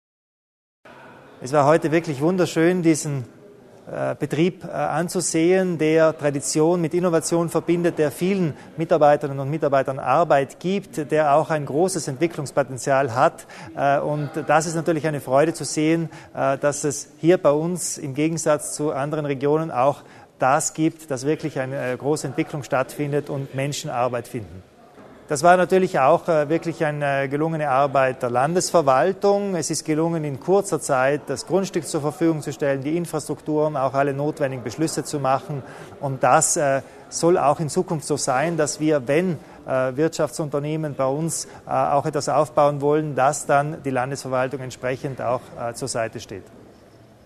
Landeshauptmann Kompatscher zur Bedeutung wirtschaftlicher Neuansiedlungen